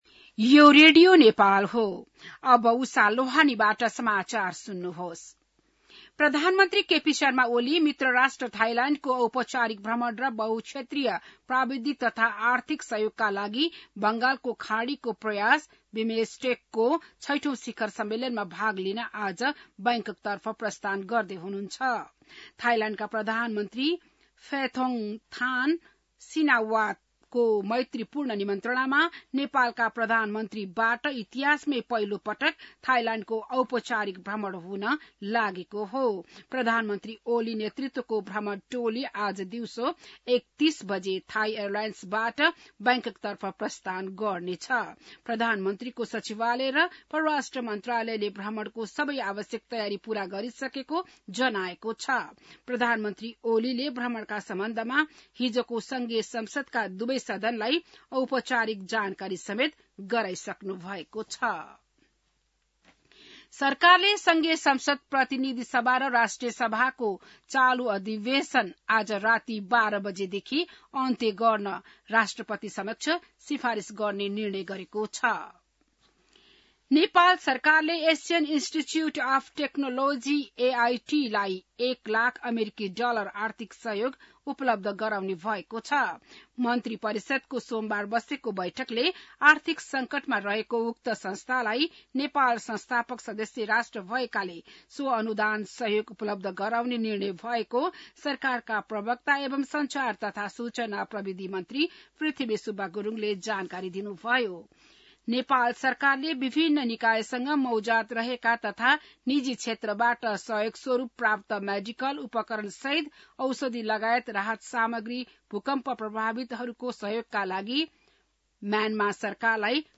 बिहान १० बजेको नेपाली समाचार : १९ चैत , २०८१